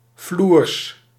Ääntäminen
IPA : /ˈdɑːk.nəs/